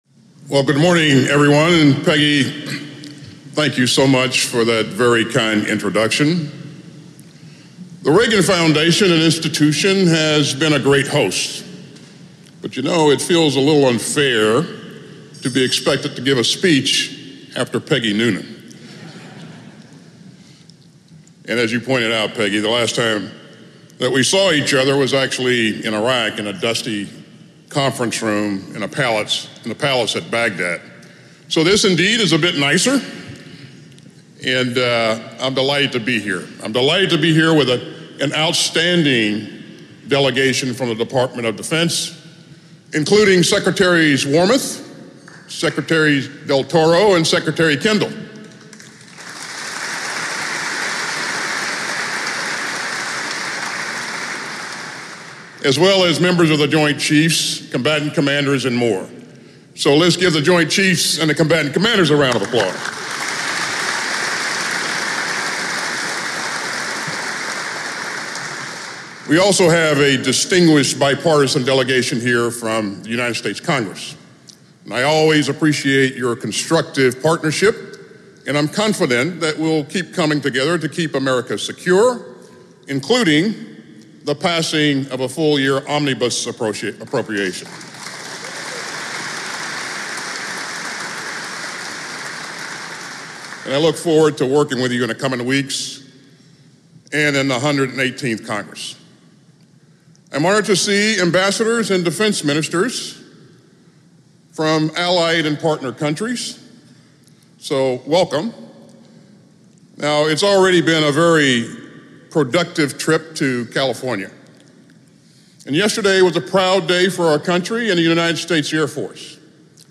lloydaustinreagandefenseforum2022ARXE.mp3